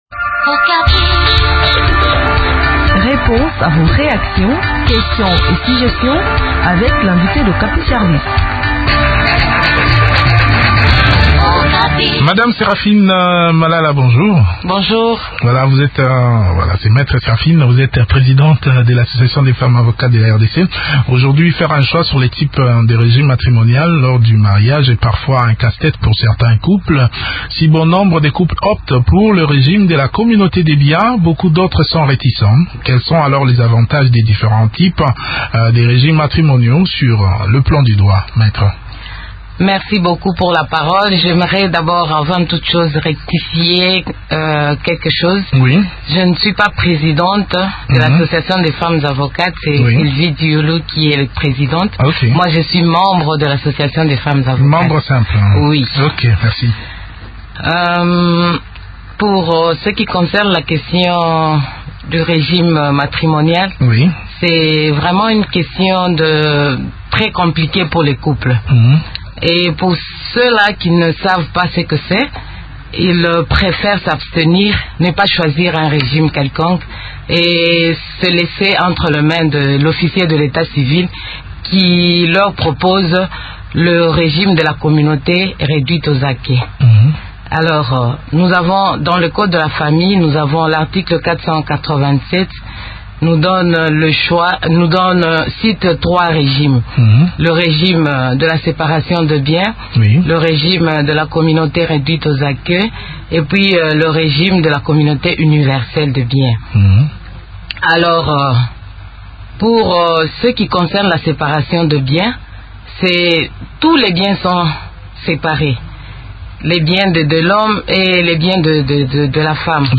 Des plus amples détails dans cette interview